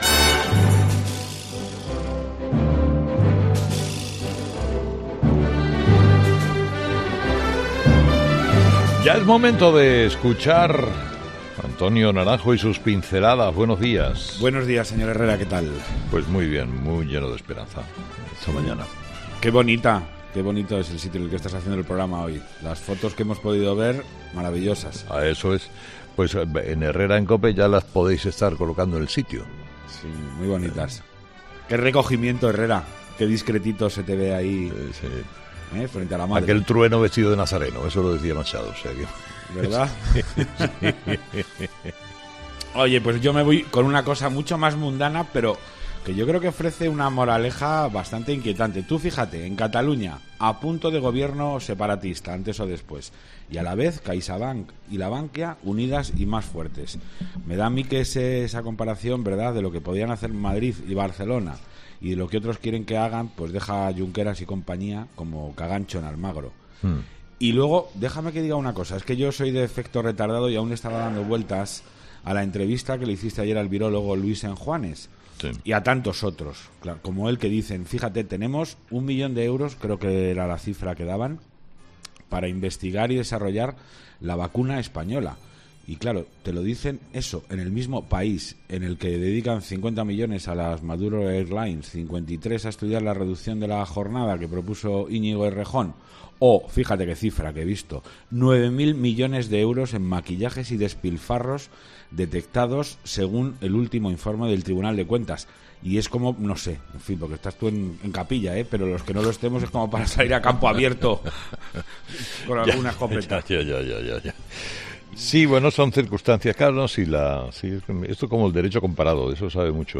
Tertulia de los oyentes